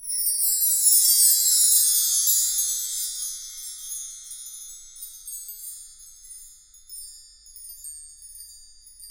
Index of /90_sSampleCDs/Roland LCDP03 Orchestral Perc/PRC_Wind Chimes1/PRC_W.Chime Down